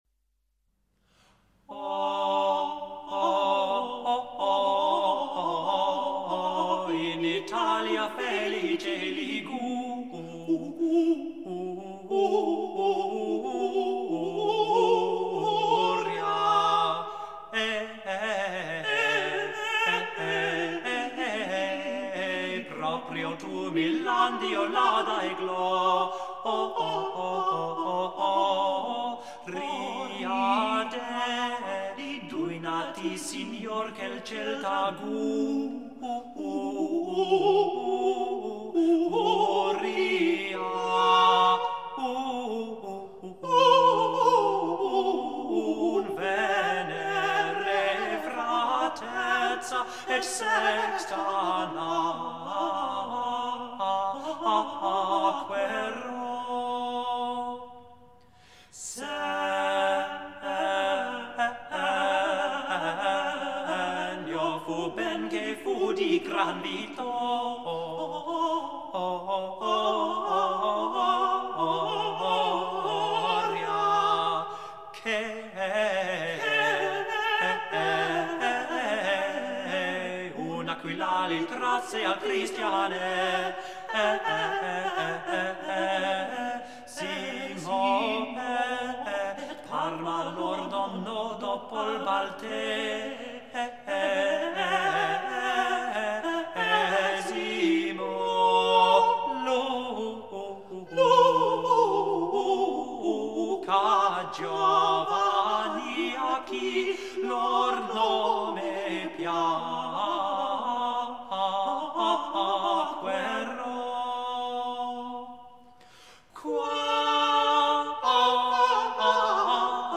O in Italia felice Liguria - Madrigale